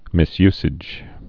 (mĭs-ysĭj, -zĭj)